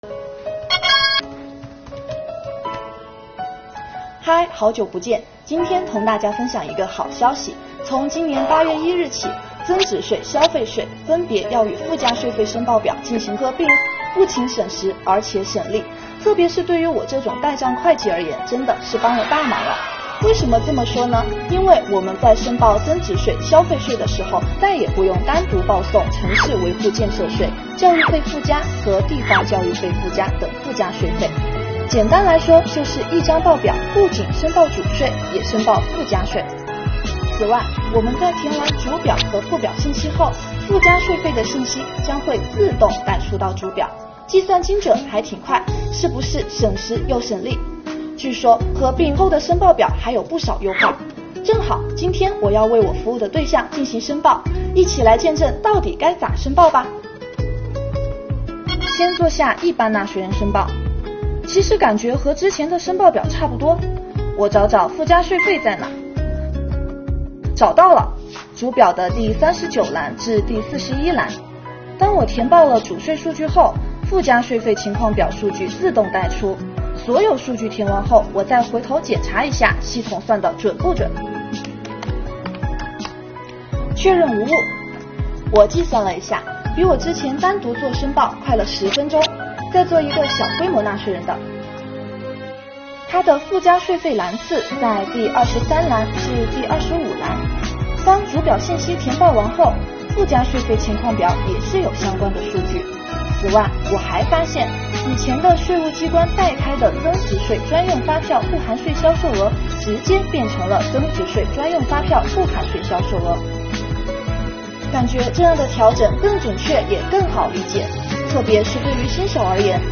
让这位代账会计小姐姐来教教您~